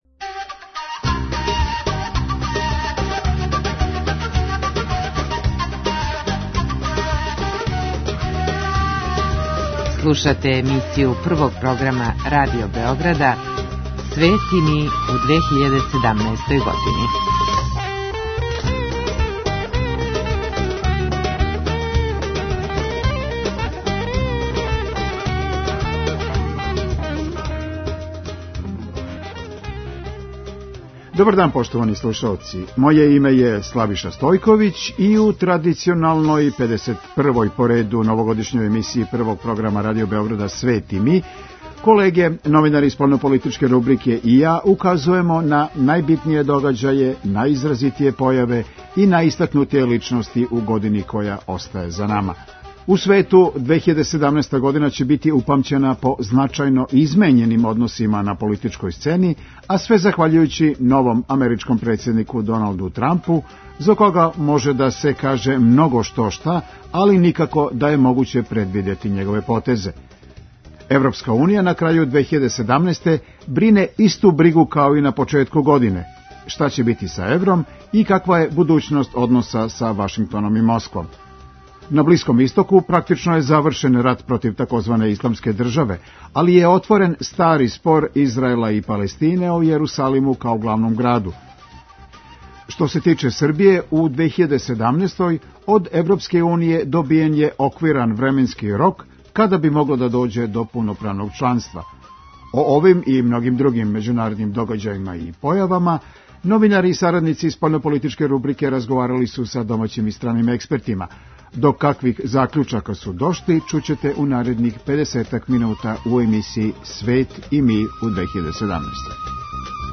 О овим и другим међународним темама које су обележиле 2017. годину, говоре домаћи и страни стручњаци.